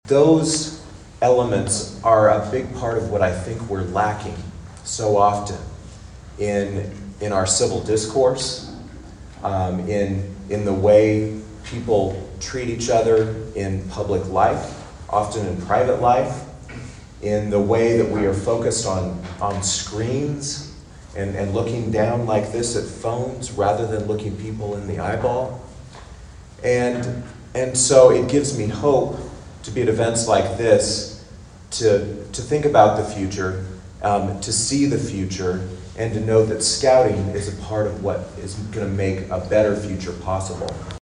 Kansas Lt. Gov. and Commerce Secretary David Toland gives the keynote address during the Jayhawk Council Sojadi District's Friends of Scouting Breakfast in Emporia on Wednesday.
The benefits of Scouting were touted during the Jayhawk Council Sojadi (soh-HAH-dee) District’s Friends of Scouting breakfast at the Emporia Arts Center on Wednesday.